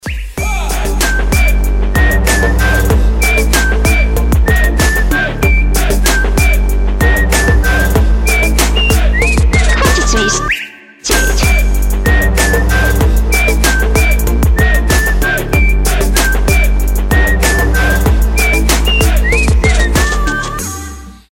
• Качество: 256, Stereo
поп
громкие
dance
RnB